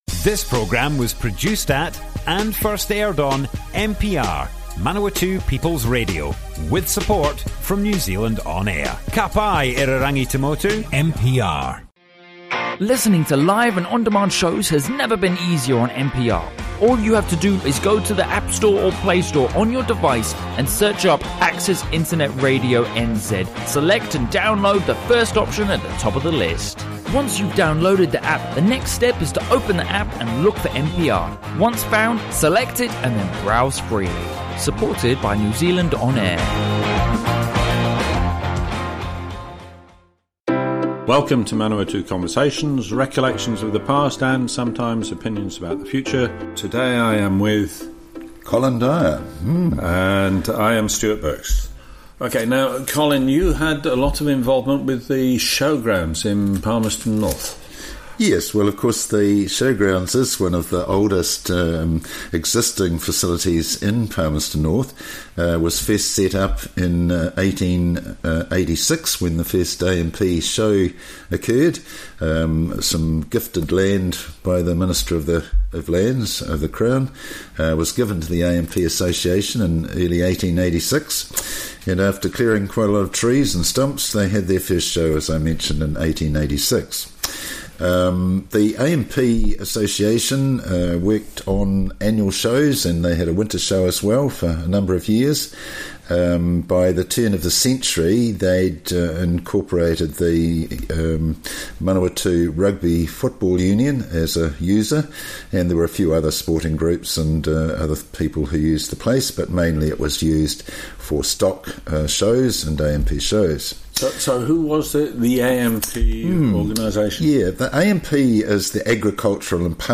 Manawatu Conversations More Info → Description Broadcast on Manawatu People's Radio 11 December 2018.
oral history